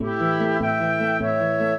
minuet10-9.wav